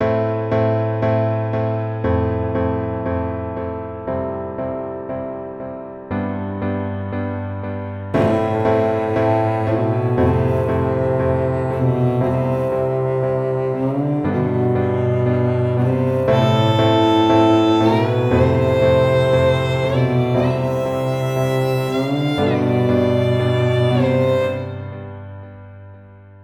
Categories: Melodies
20 High-Quality String Melodies Made Completely From Scratch.
Beyond-The-Light_118BPM_Amin.wav